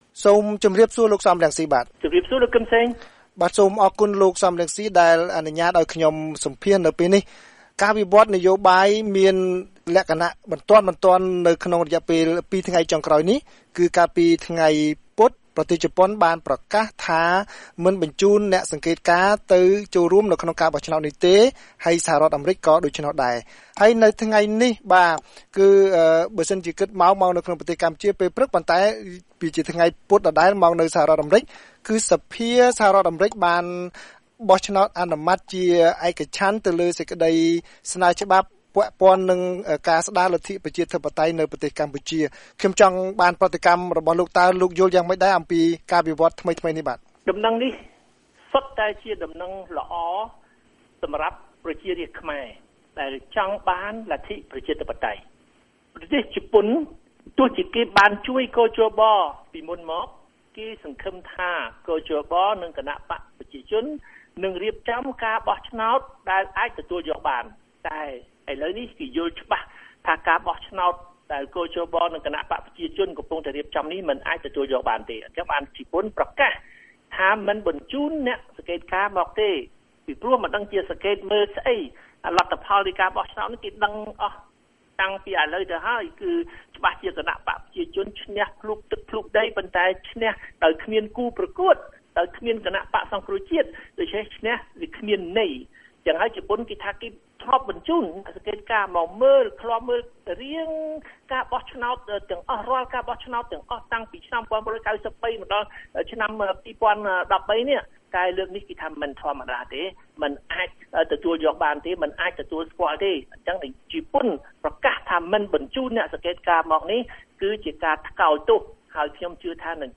បទសម្ភាសន៍ VOA៖ លោក សម រង្ស៊ី អបអរសេចក្តីព្រាងច្បាប់លេខ៥៧៥៤របស់សភាអាមេរិកាំង